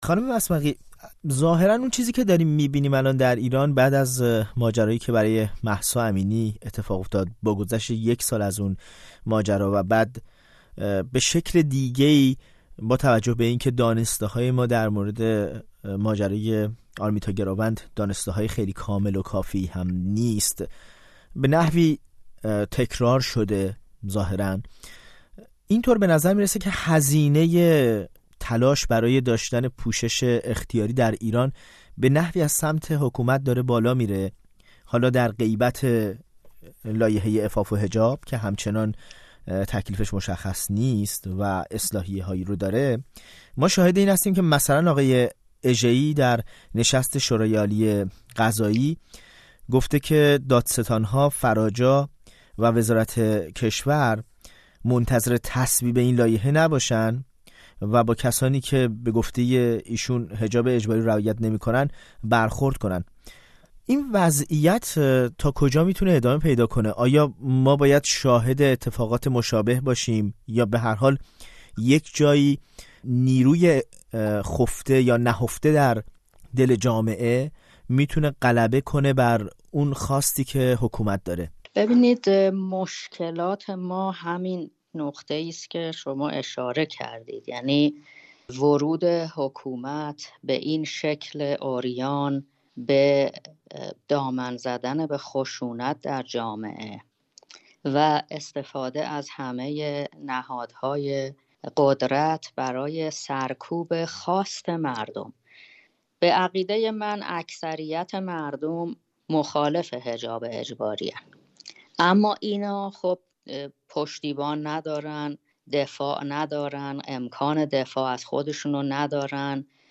نقدی برسیاست «تنش‌آفرین حجاب اجباری» در ایران در گفت‌‌وگو با صدیقه وسمقی